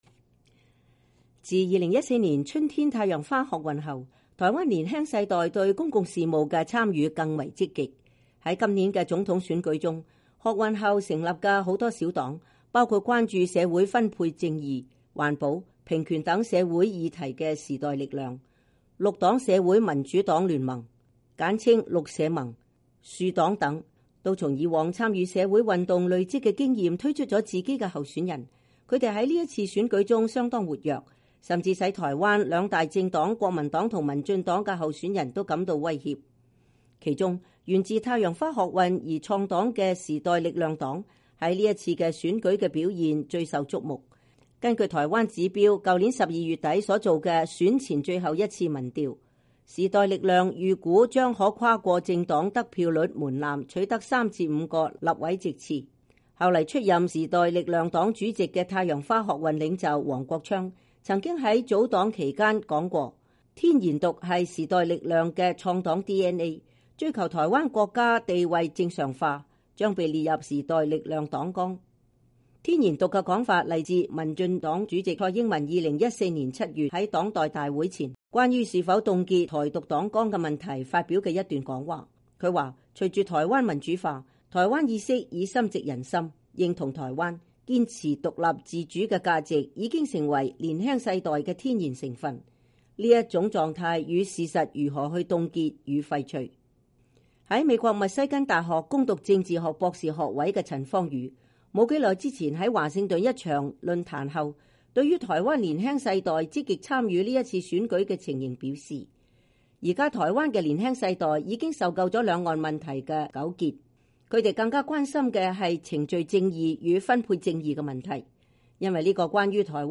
震天響的音樂，上萬觀眾的歡呼吶喊，這是一場重金屬搖滾演唱會，也是一場政治造勢晚會。